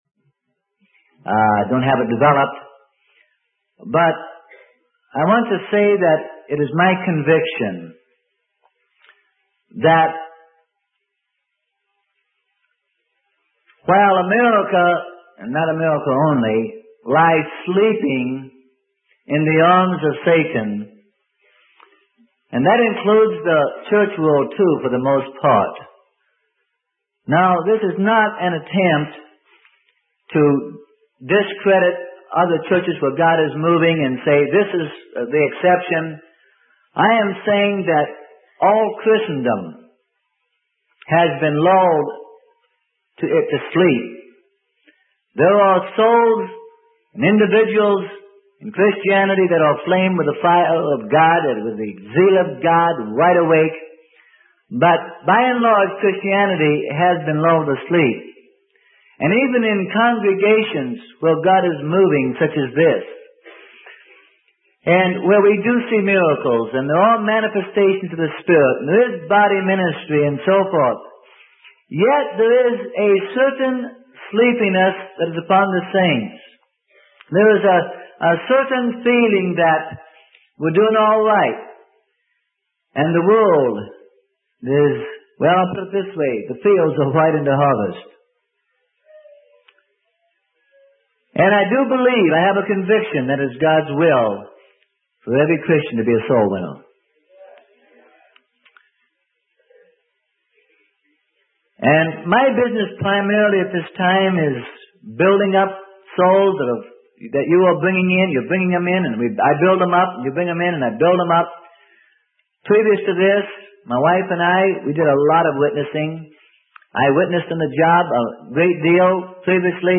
Sermon: Only Believe - Freely Given Online Library